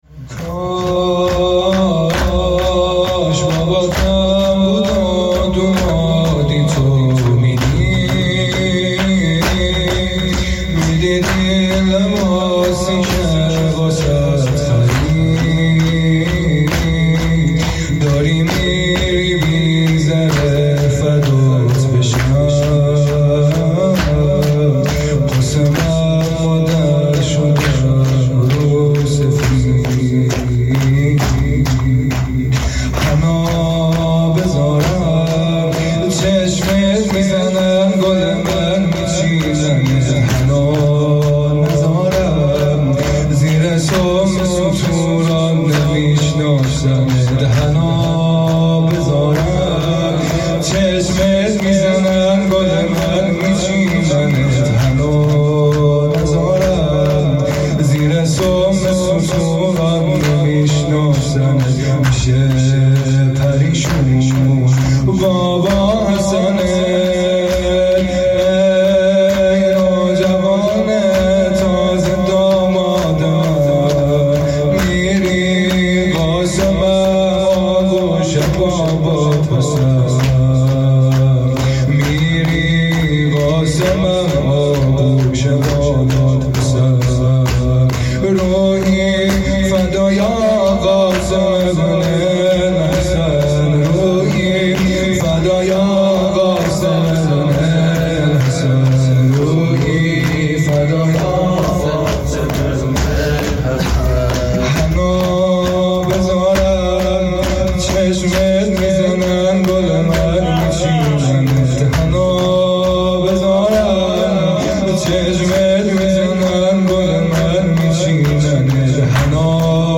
بخش ششم شور پایانی
شب ششم محرم الحرام 144۳ | هیئت کاروان حسینی (ع) | ۲۳ مرداد 1400